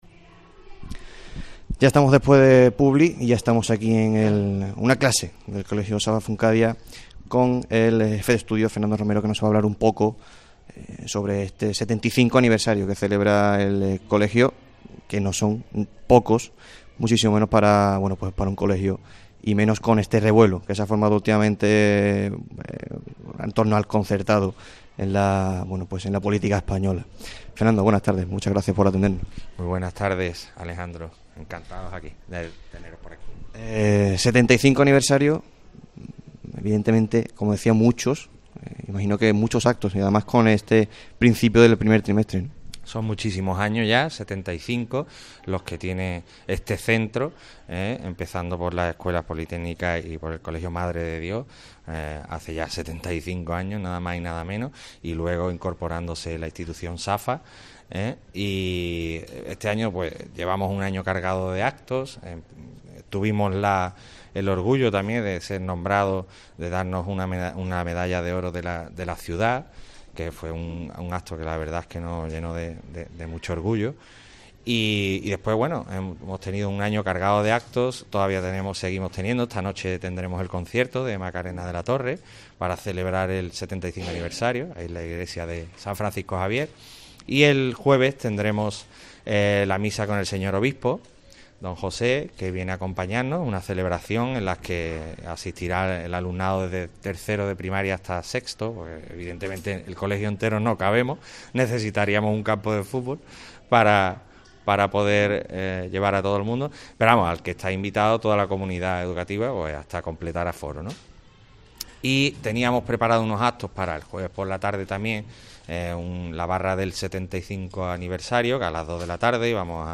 atiende a COPE Huelva desde una de las aulas del colegio para explicar los actos del 75 aniversario.